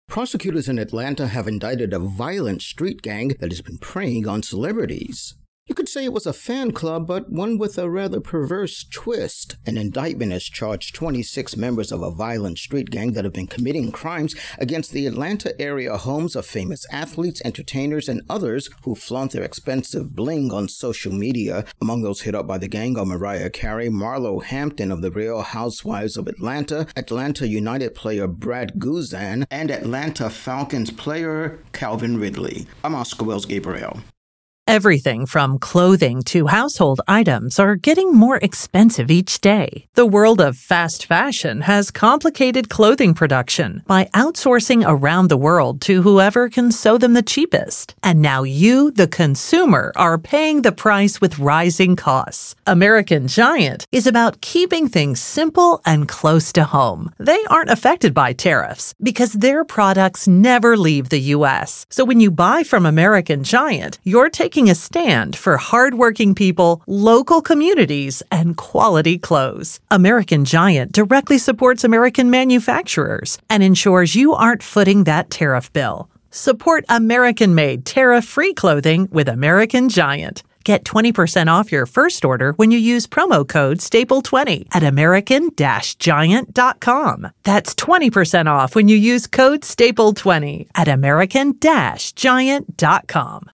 AP entertainment correspondent